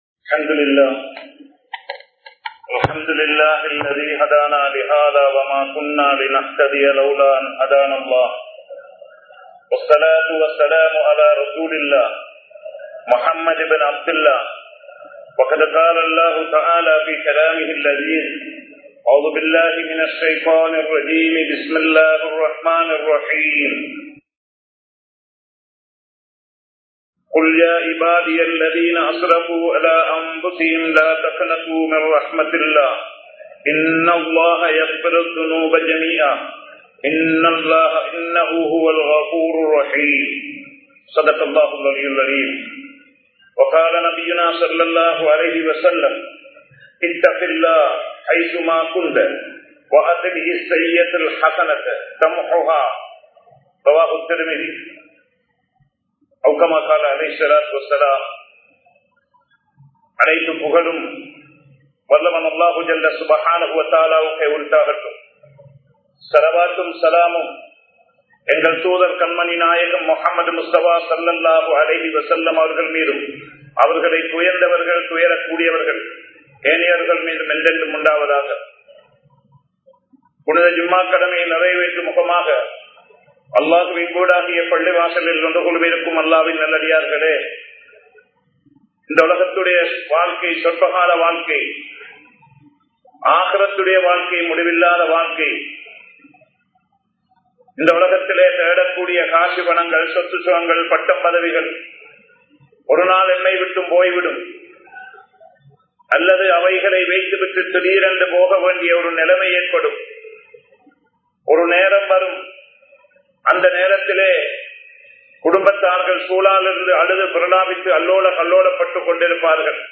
ரமழானுக்குத் தயாராகுவோம் | Audio Bayans | All Ceylon Muslim Youth Community | Addalaichenai
Kollupitty Jumua Masjith